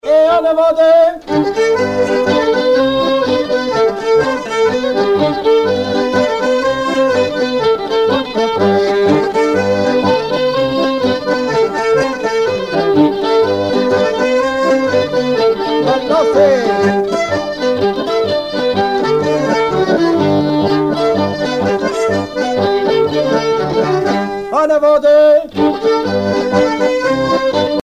danse : quadrille : avant-deux
Pièce musicale éditée